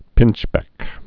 (pĭnchbĕk)